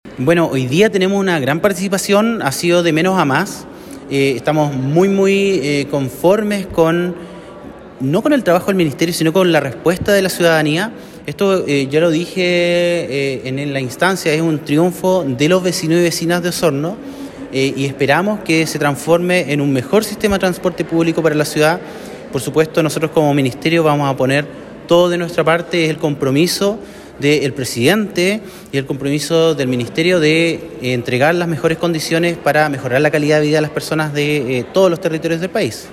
El Seremi de Transportes y Telecomunicaciones de Los Lagos, Pablo Joost, indicó que la respuesta de la ciudadanía fue muy positiva, lo que contribuye a crear un mejor sistema de transporte público para Osorno.